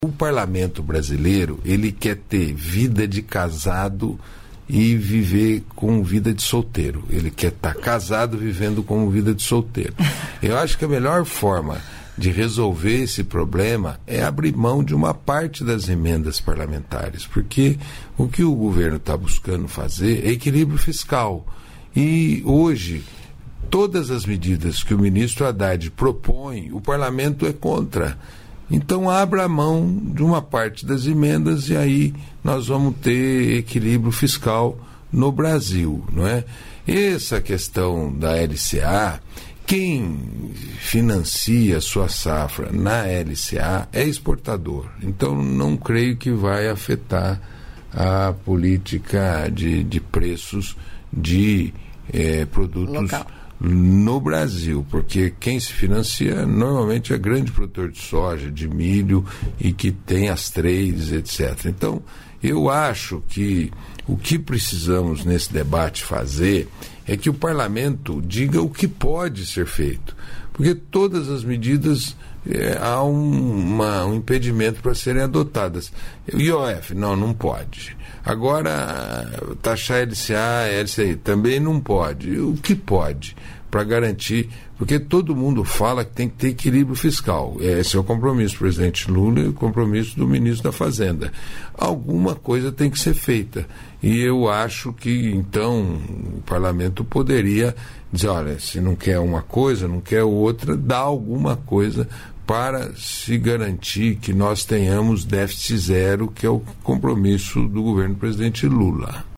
Trecho da participação do ministro do Desenvolvimento Agrário e Agricultura Familiar do Brasil, Paulo Teixeira, no programa "Bom Dia, Ministro" desta terça-feira (10), nos estúdios da EBC em Brasília (DF).